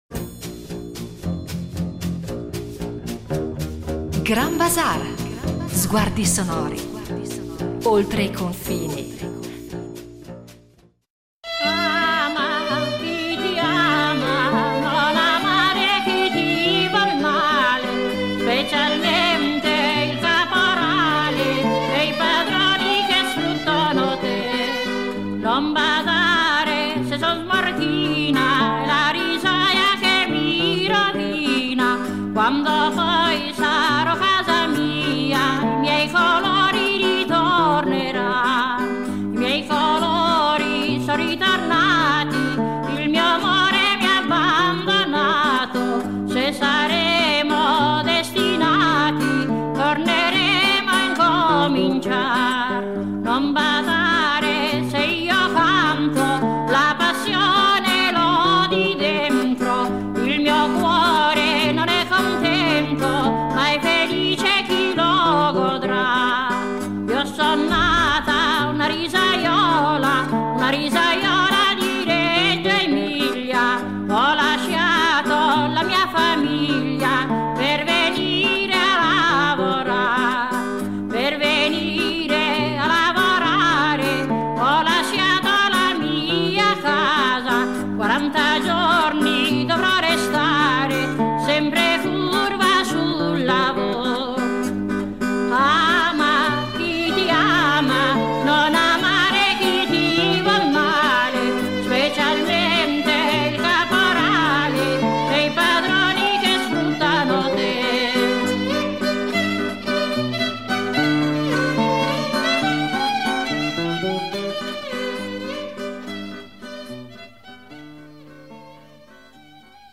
Giovanna Daffini , una voce potente, unica e irripetibile che ha fatto la storia della canzone popolare italiana cantando la miseria, la guerra, l’emarginazione, il lavoro, la rivincita e la speranza.
Il timbro aspro e corrosivo.